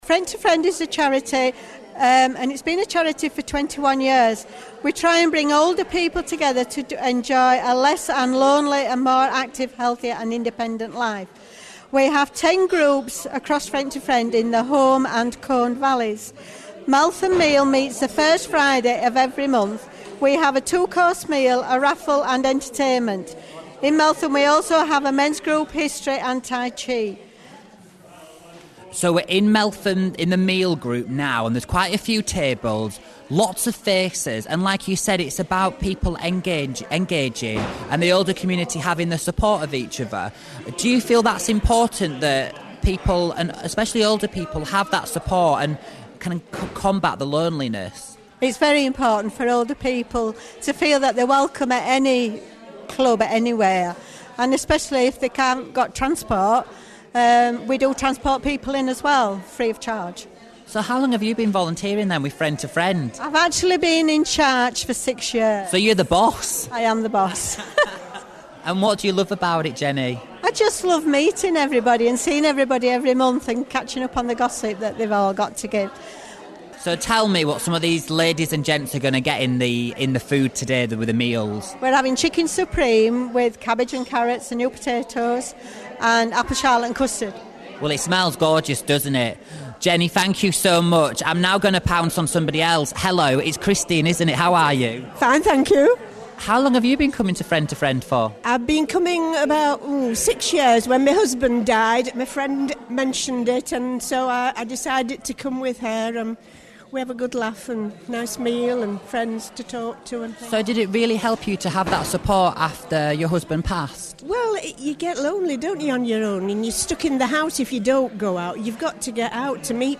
As promised, here is Meltham Meal as featured on Radio Leeds.